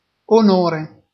Ääntäminen
US : IPA : [krɛ.dɪt]